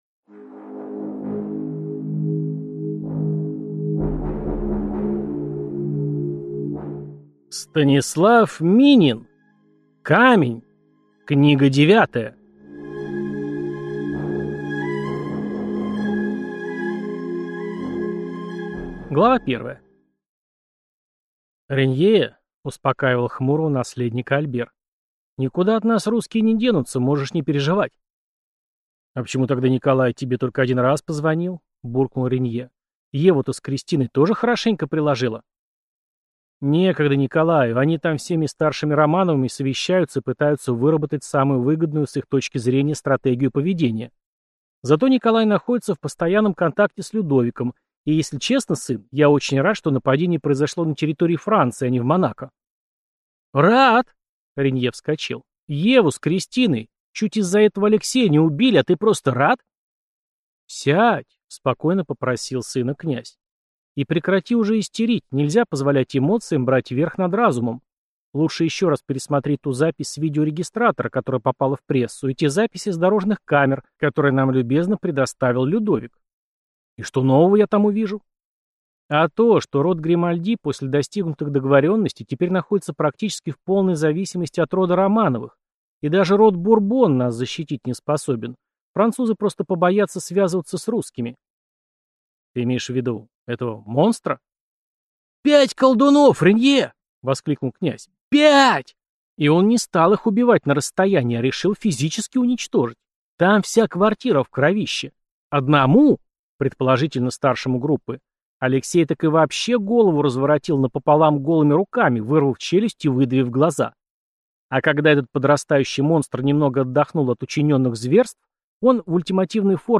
Аудиокнига Камень. Книга девятая | Библиотека аудиокниг